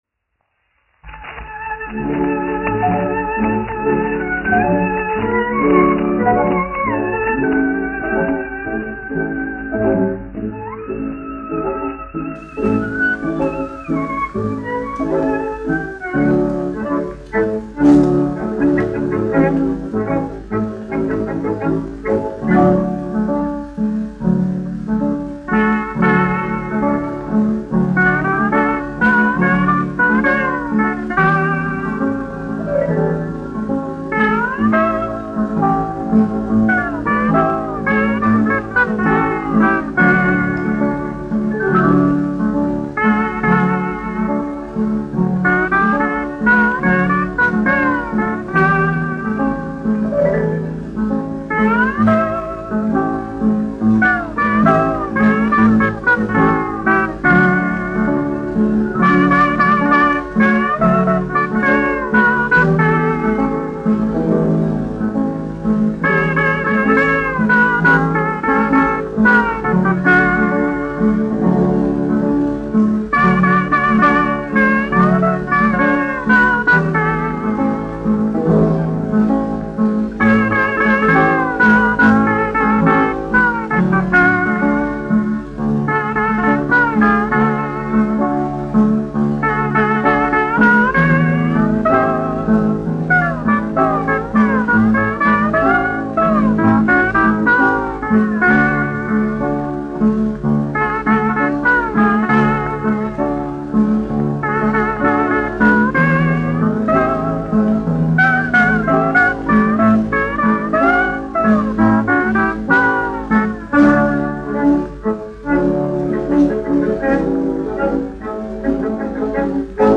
1 skpl. : analogs, 78 apgr/min, mono ; 25 cm
Populārā instrumentālā mūzika
Skaņuplate
Latvijas vēsturiskie šellaka skaņuplašu ieraksti (Kolekcija)